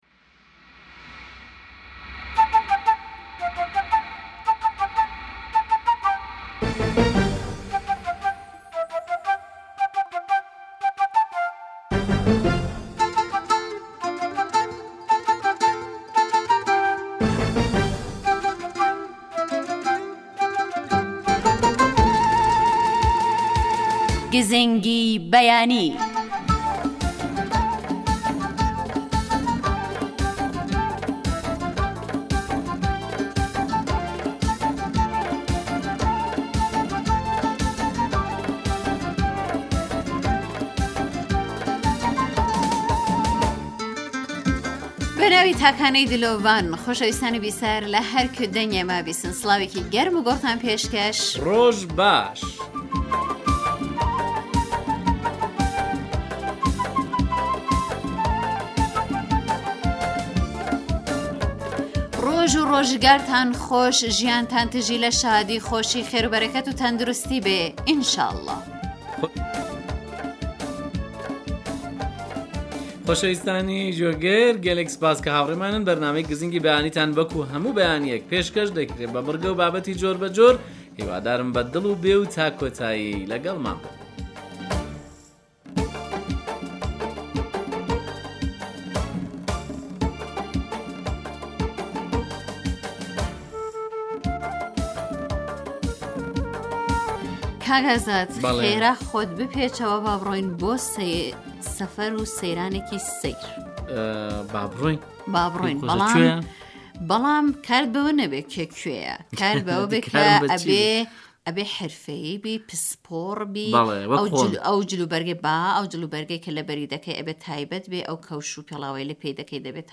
گزینگی بەیانی بەرنامەیێكی تایبەتی بەیانانە كە هەموو ڕۆژێك لە ڕادیۆ كەردی تاران بڵاو دەبێتەوە و بریتییە لە ڕاپۆرت و دەنگی گوێگران و تاووتوێ كردنی بابەتێكی پ...